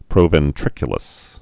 (prōvĕn-trĭkyə-ləs)